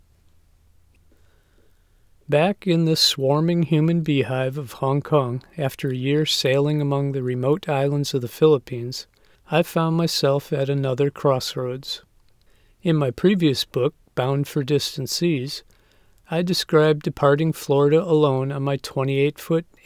I’m using Win 8.1 with a Blue Yeti (60% gain, Pop filter, 8" distance) and 2.2.2 Audacity.
Unfortunately, I have a very noticeable whistle sibilance when I pronounce s or sh.
Here’s a 20 second unmastered sample that I hope you can have a listen to and give me some ideas.
Actually, you don’t. You have plain sibilance, an evil boosting of tones between about 4000Hz and 7000Hz.